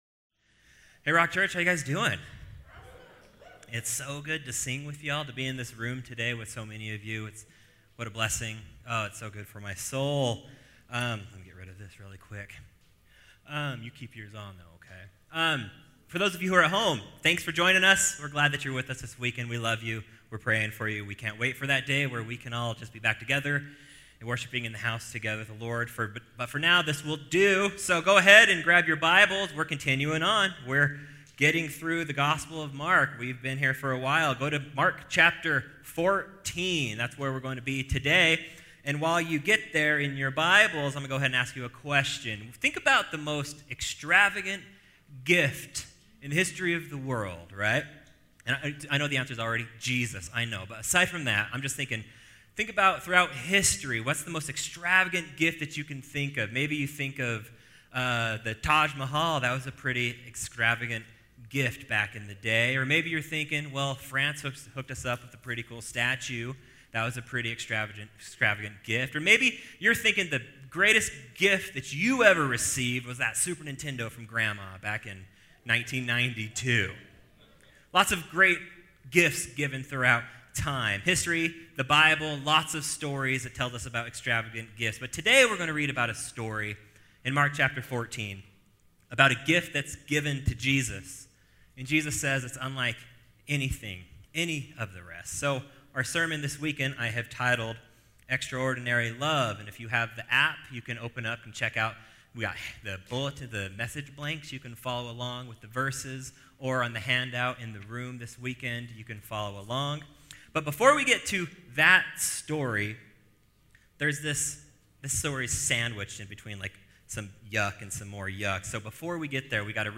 Get Wisdom: Practical Wisdom for Life from the Book of Proverbs, is a 14-week sermon series from The Rock Church in Draper Utah.